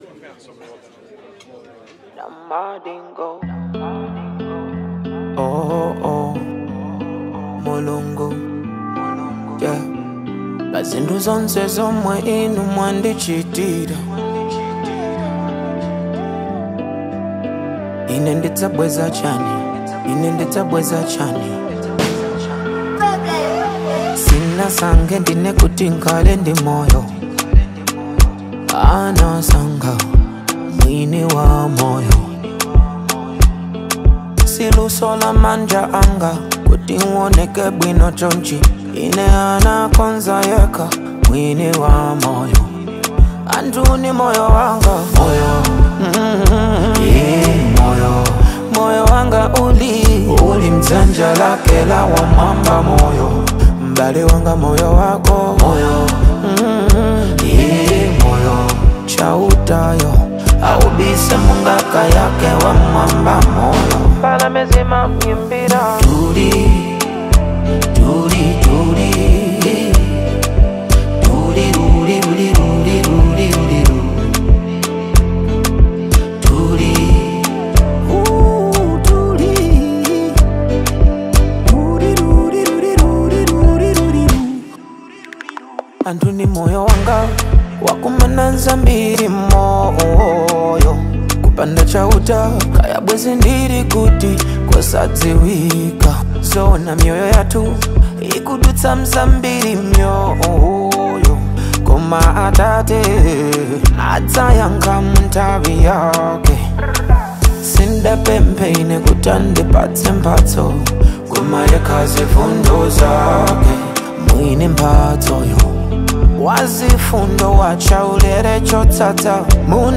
passionate delivery